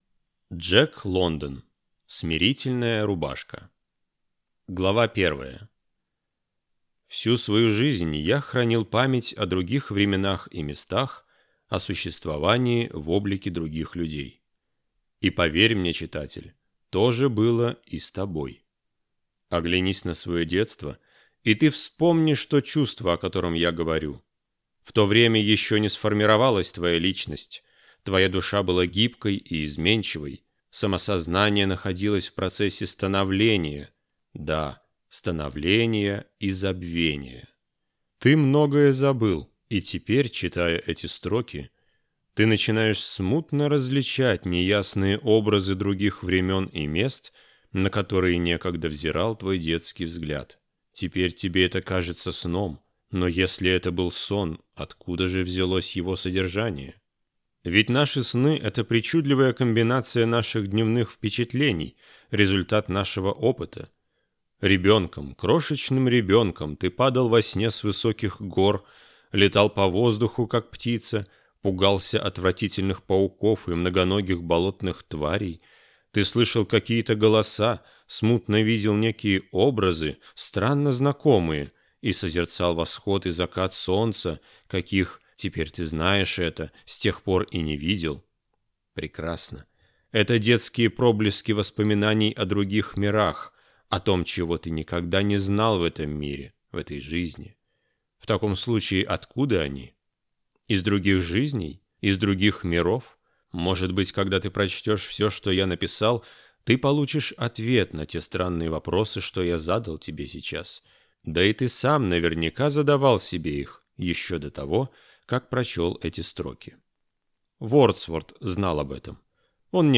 Аудиокнига Смирительная рубашка. Когда боги смеются (сборник) | Библиотека аудиокниг